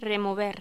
Locución: Remover
Sonidos: Voz humana